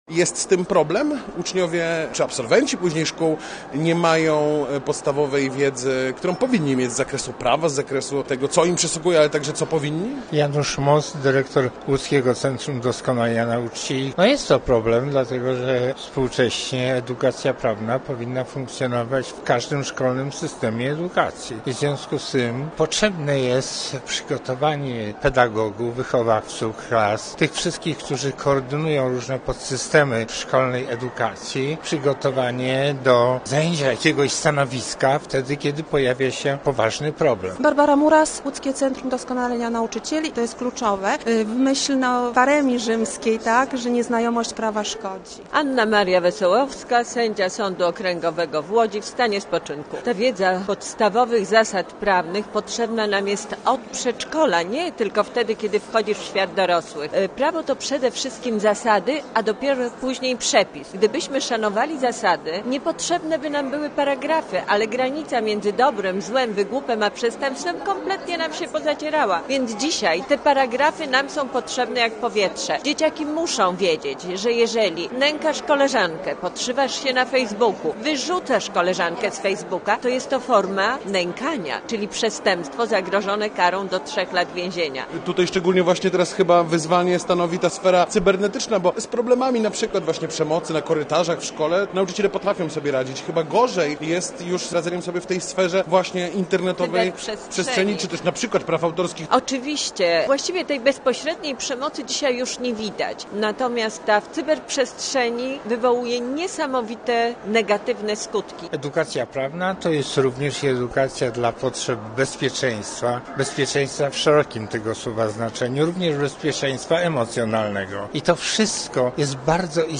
Uczniowie nie mają podstawowej wiedzy prawnej, a pedagodzy nie są przygotowani do jej przekazywania. Takie wnioski płyną z konferencji, która odbyła się w ramach 21. Łódzkich Targów Edukacyjnych.
Posłuchaj relacji naszego reportera i dowiedz się więcej: Nazwa Plik Autor Prawo w szkole audio (m4a) audio (oga) Warto przeczytać Pieniądze na sport w województwie łódzkim. 12 obiektów przejdzie remont 9 lipca 2025 Niż genueński w Łódzkiem.